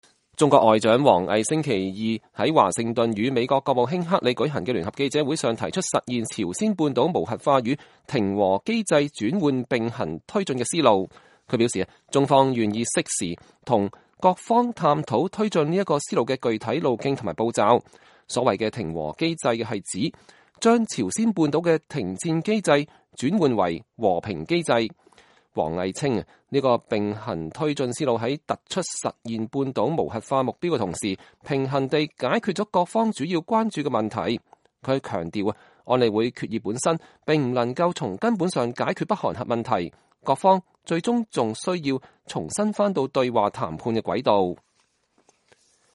中國外長王毅星期二在華盛頓與美國國務卿克里舉行的聯合記者會上提出實現朝鮮半島無核化與停和機制轉換併行推進的思路。